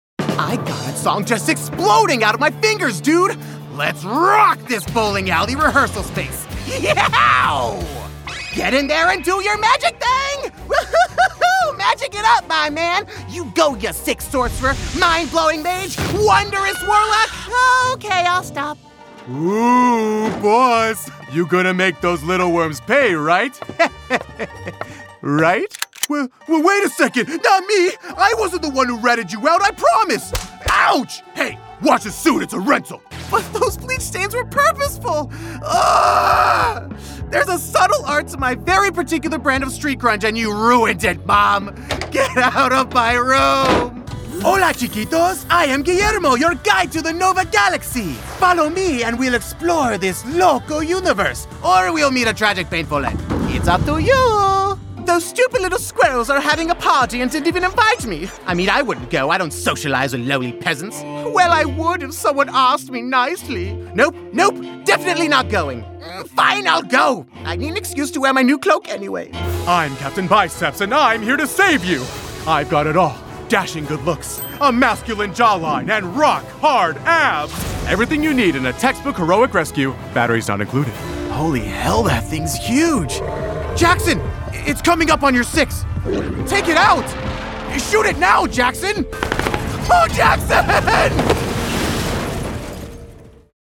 Versatile Male Voice over Talent